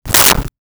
Whip 03
Whip 03.wav